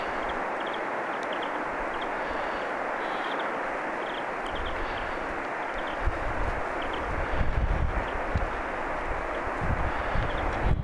viherpeipon ääntelyä
viherpeipon_aantelya.mp3